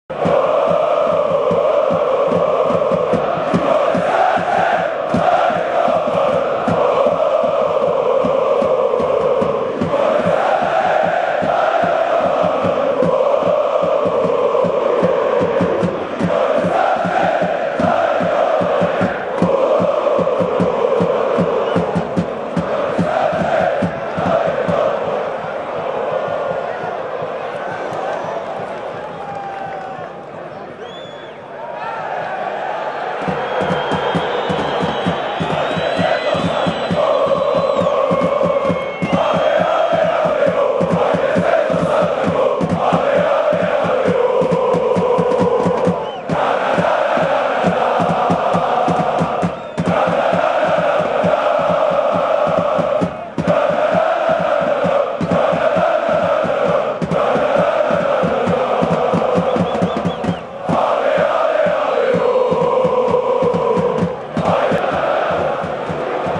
135derbi_navijanje.wma